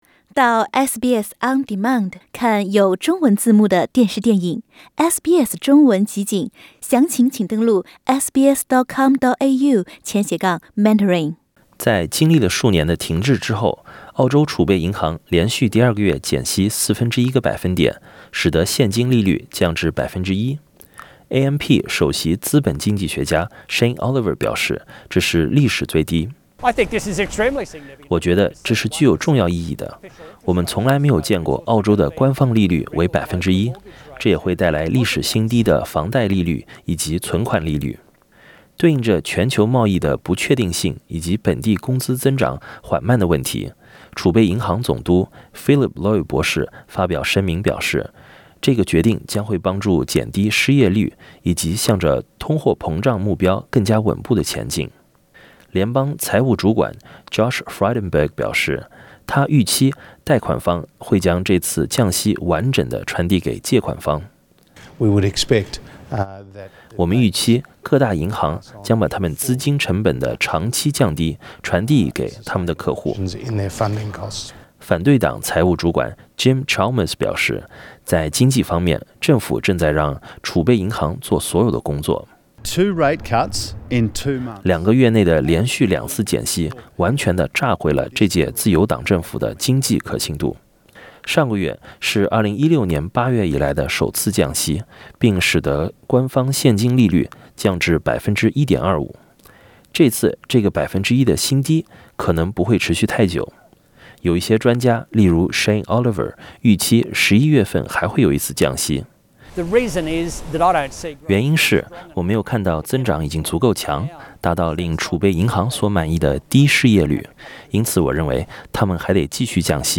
(AAP) Source: AAP SBS 普通话电台 View Podcast Series Follow and Subscribe Apple Podcasts YouTube Spotify Download (6.32MB) Download the SBS Audio app Available on iOS and Android 澳洲储备银行连续两个月降息，使得官方现金利率达到澳洲历史最低。